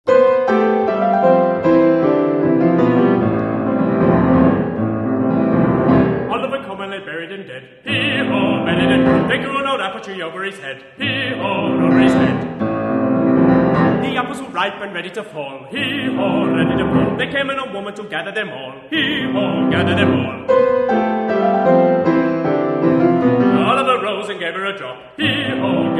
English and American folk, traditional and art songs.
baritone
pianist
Classical, Vocal